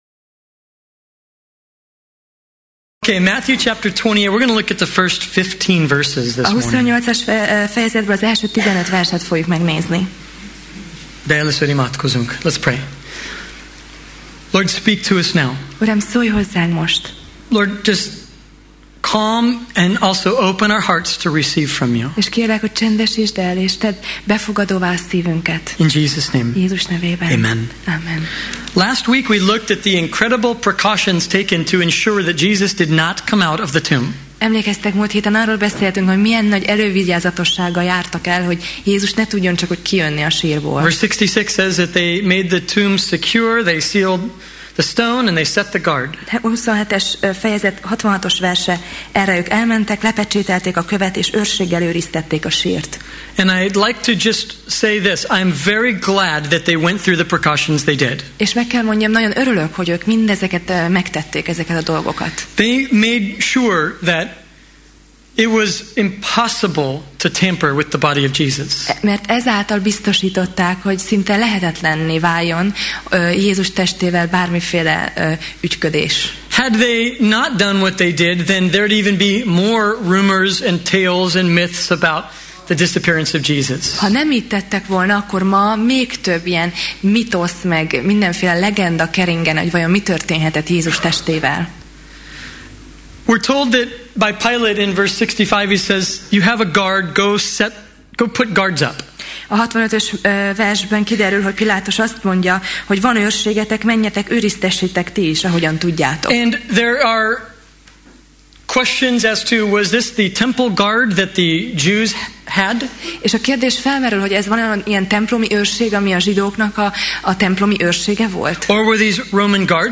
Máté Passage: Máté (Matthew) 28:1–15 Alkalom: Vasárnap Reggel